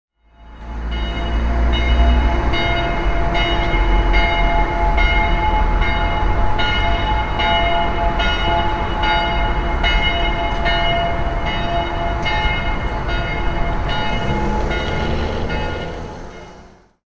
City Bells Sound Effect
Church bells ringing in an urban environment. The tolling of church bells, accompanied by the distant sound of cars in the background. Street noise.
City-bells-sound-effect.mp3